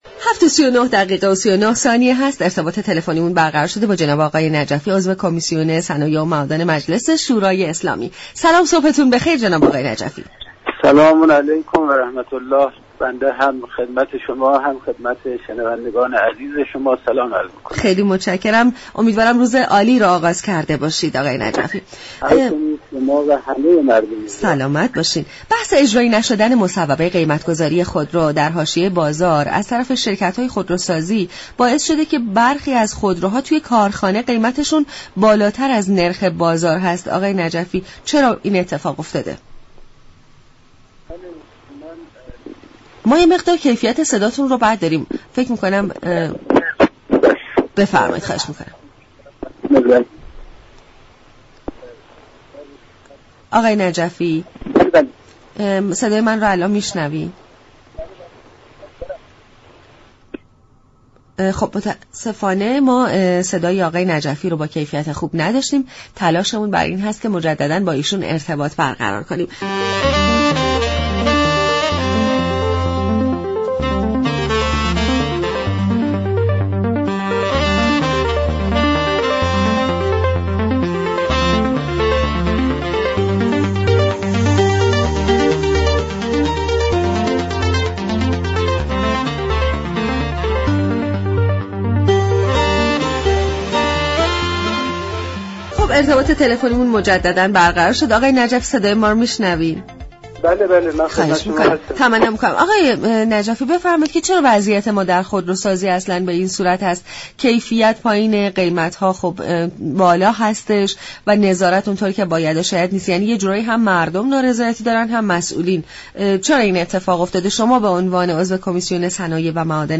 به گزارش شبكه رادیویی ایران، «محمدرضا نجفی»، عضو كمیسیون صنایع و معادن مجلس در گفت و گو با برنامه «سلام صبح بخیر» در این باره گفت: ذینفعان صنعت خودرو اعم از مردم، سهام داران، دولت و حاكمیت امروز از وضعیت بازار خودرو احساس زیان و نارضایتی دارند.